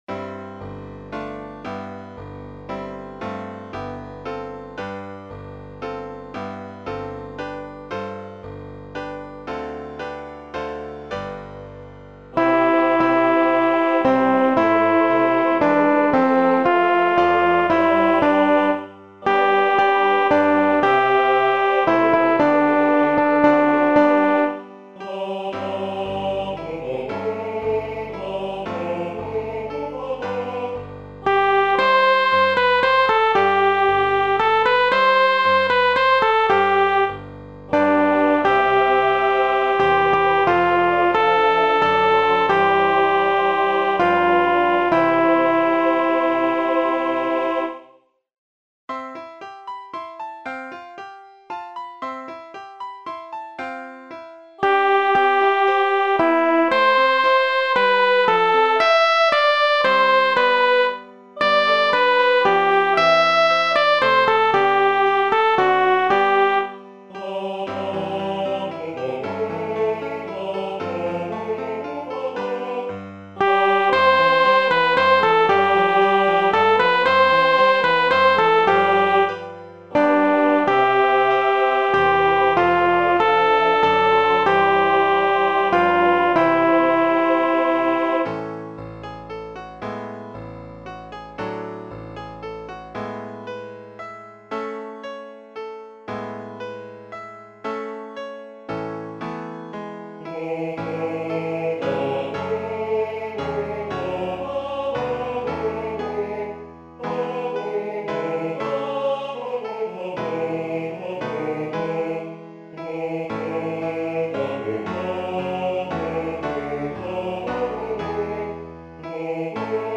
アルト（フレットレスバス音）